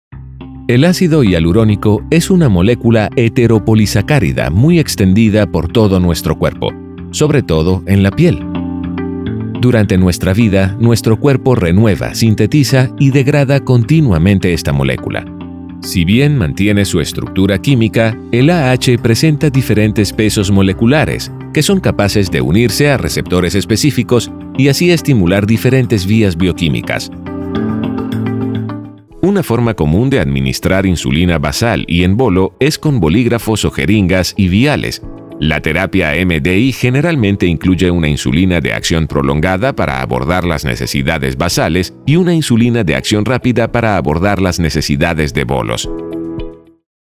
Male
Authoritative, Bright, Character, Confident, Corporate, Deep, Engaging, Friendly, Gravitas, Natural, Smooth, Warm, Versatile
Latin American Spanish, South American Spanish, Mexican Spanish, Venezuelan Spanish, English with a Spanish accent.
Voice reels
Microphone: AKG, Rode